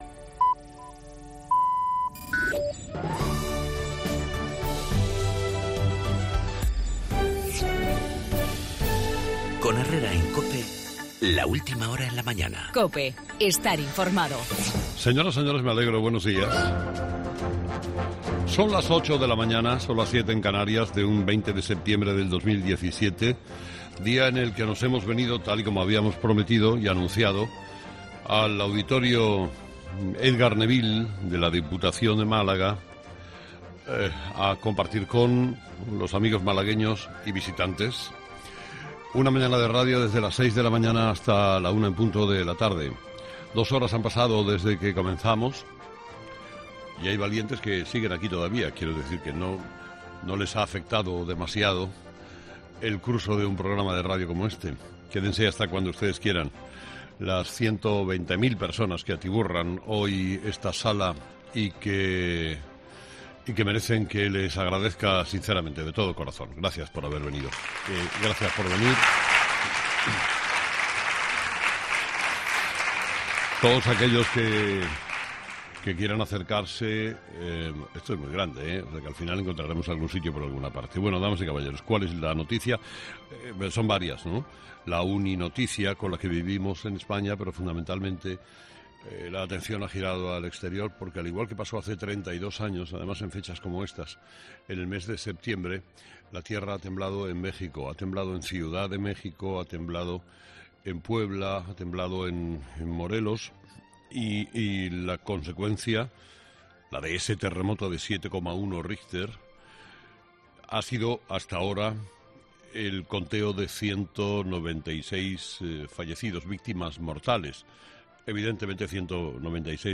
El Congreso no apoya al Gobierno en la defensa de la legalidad en Cataluña con el voto en contra del PSOE, en el editorial de Carlos Herrera
Día que nos hemos venido al Auditorio Edgar Neville de Málaga para compartir una mañana de radio hasta la 1 de la tarde.